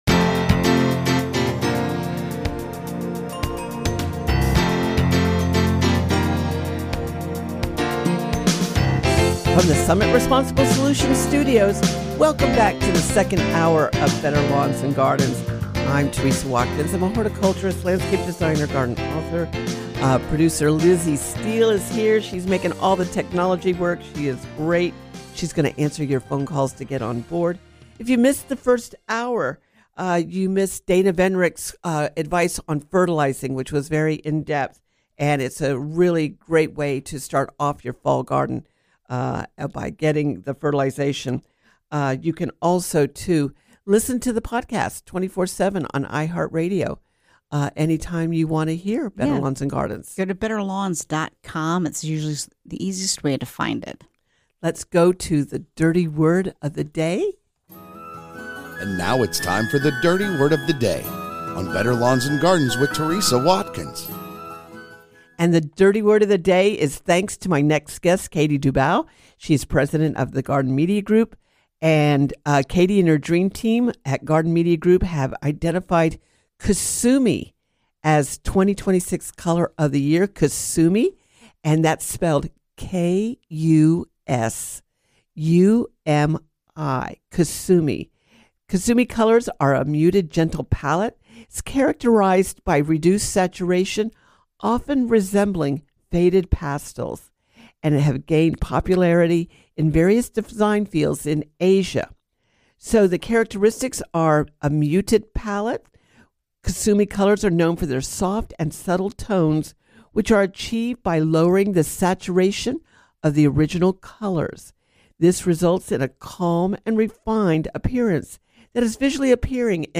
Better Lawns and Gardens Hour 2 – Coming to you from the Summit Responsible Solutions Studios.